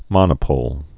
(mŏnə-pōl)